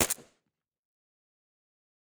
pistol_1.ogg